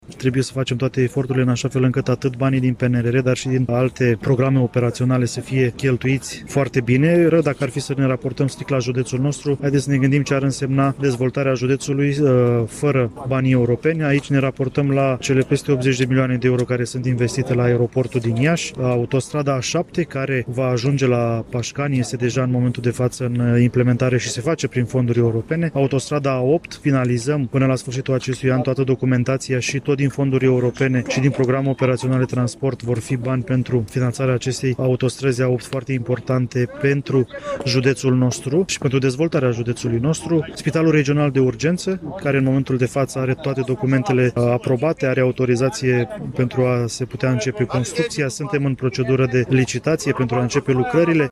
La Iași, au fost marcate, astăzi, Ziua Independenței Naționale, Ziua Victoriei Coaliției Națiunilor Unite în cel de-al doilea Război Mondial și Ziua Uniunii Europene, în cadrul unor ceremonii militare și religioase organizate în Piața Independenței.
În cadrul evenimentului, prefectul Bogdan Cojocaru a subliniat importanța fondurilor europene care vor ajunge la Iași, dar și care sunt investițiile majore.